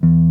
JAZZ SOLO 1-.wav